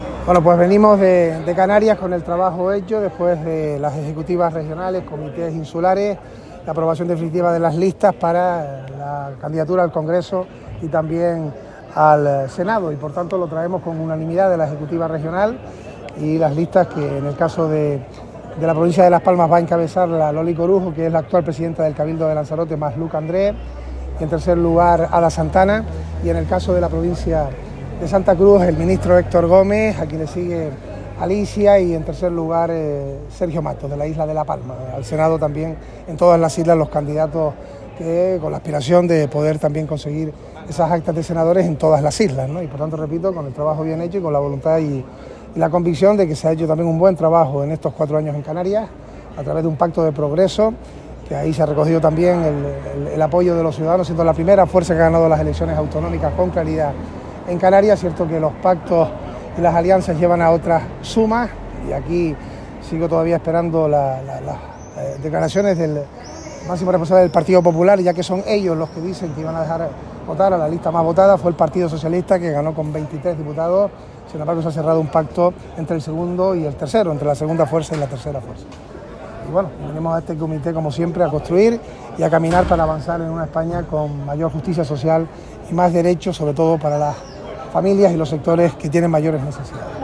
En sus declaraciones a los medios, el secretario general Ángel Víctor Torres, que ha acudido a la cita en Ferraz encabezando la delegación canaria, ha aprovechado para reivindicar el «buen trabajo» realizado durante los últimos cuatro años en Canarias por un gobierno de progreso que ha velado en todo momento por el interés de la mayoría social del Archipiélago.